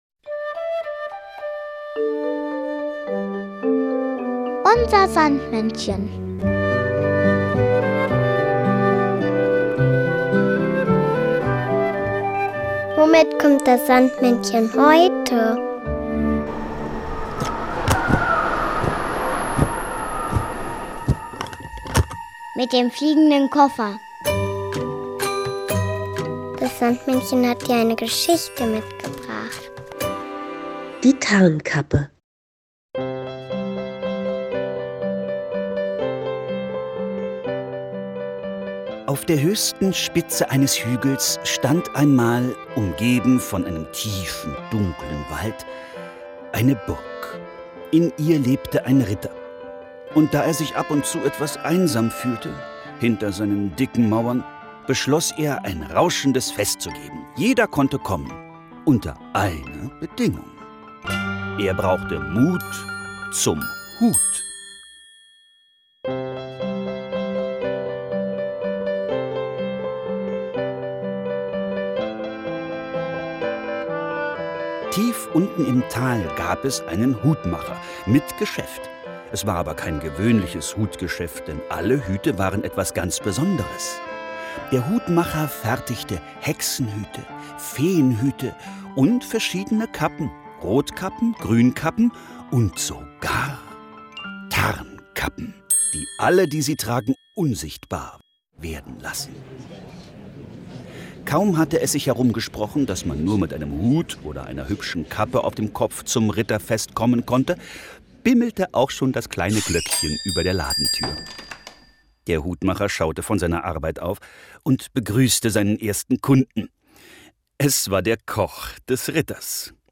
Märchen: Die Tarnkappe